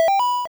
hover, die, pickup sounds
pickUp.wav